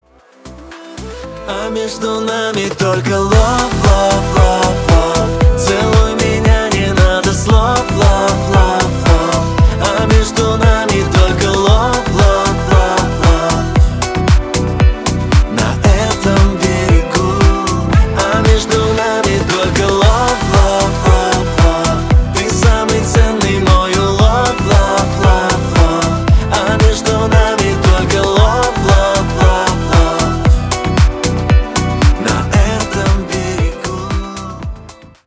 Русские рингтоны , грустные
душевные